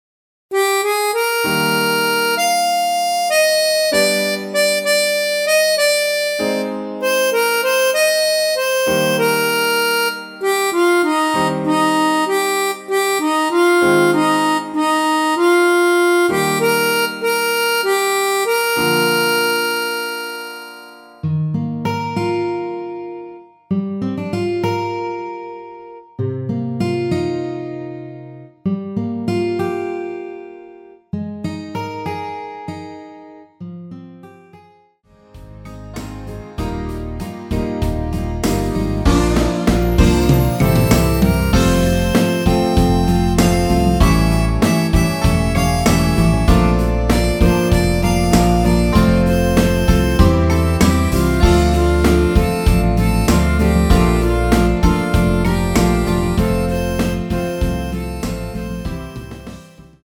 남성분이 부르실수 있는 키로 제작 하였습니다.(미리듣기 참조)
Eb
앞부분30초, 뒷부분30초씩 편집해서 올려 드리고 있습니다.